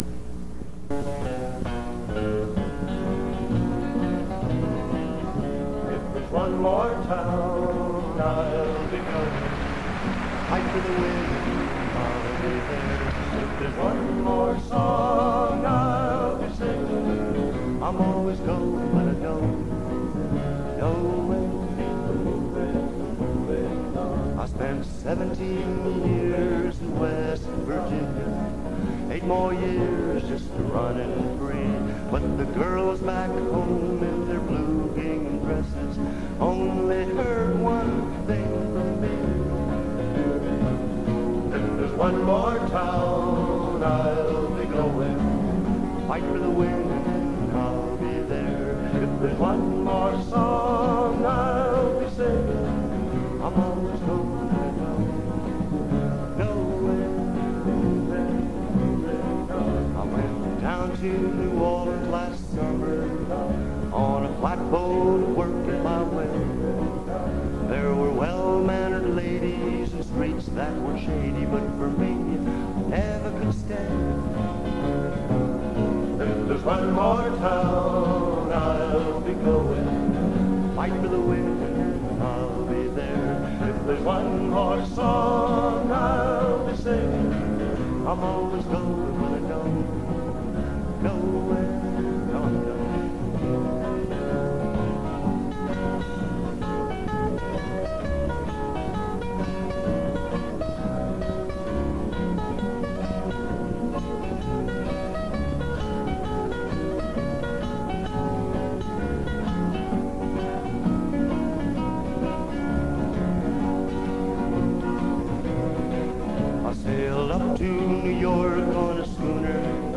This is a bootleg of a concert from sometime in 1963.